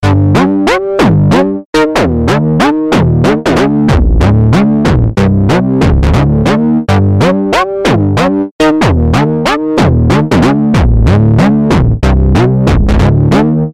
描述：为博丁合成器和古典钢琴+吉他制作的恍惚低音
Tag: 120 bpm Trance Loops Bass Synth Loops 1.35 MB wav Key : Unknown